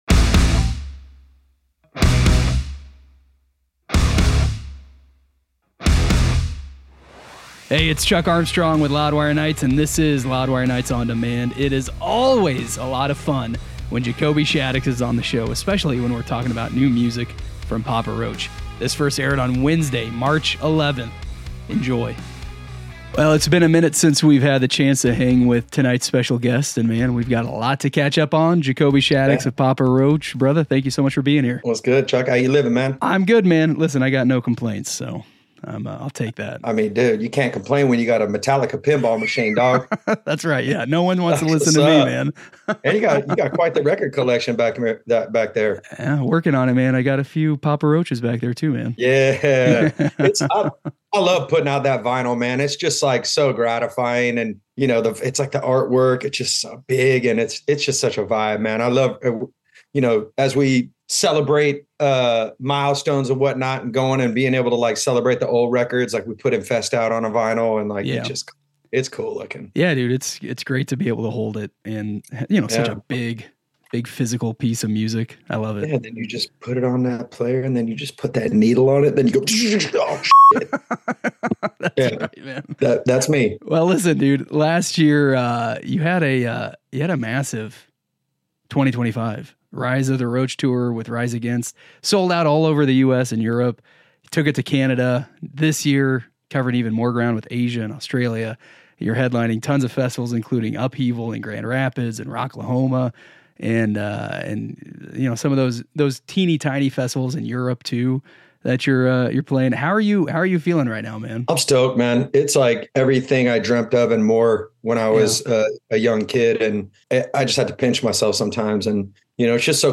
Jacoby Shaddix Interview: 'A Personal Revolution + a Personal Awakening'